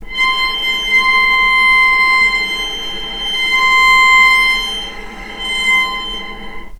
vc_sp-C6-mf.AIF